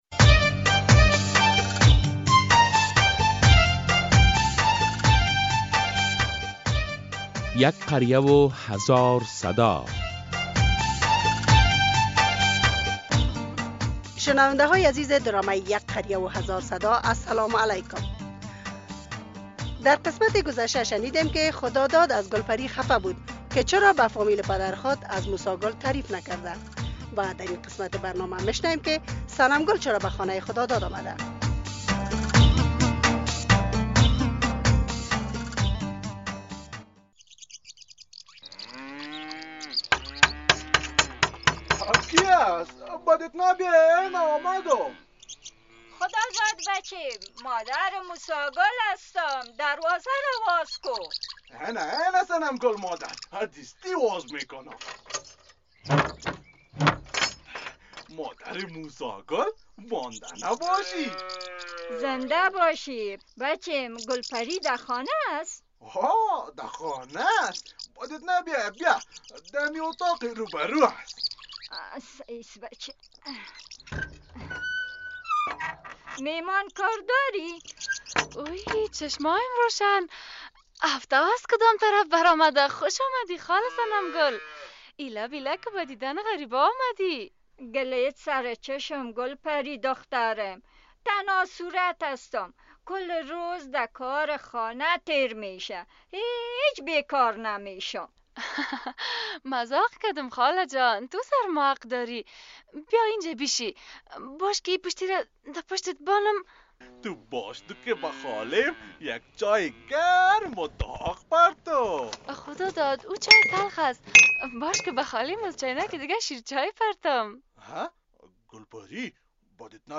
این موضوع بحث زنده امروز ...